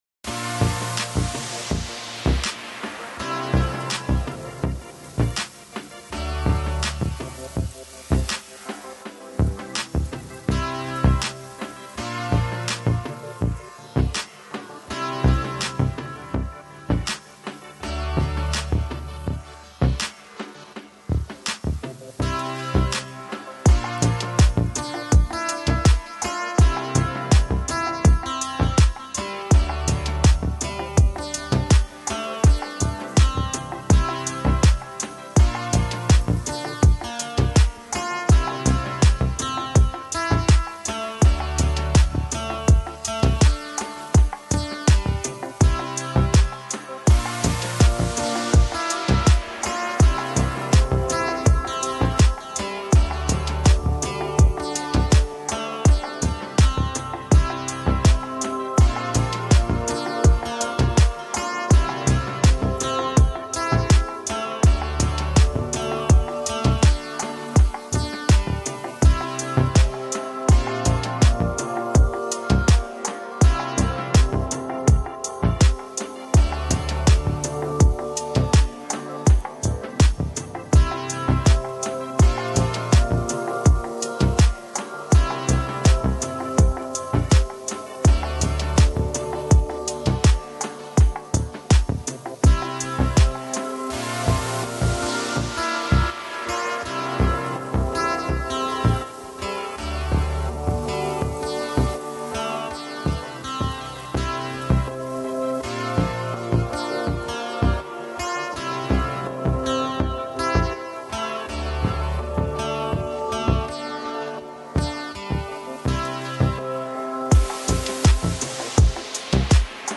Lounge, Chill Out, Downtempo, Trip Hop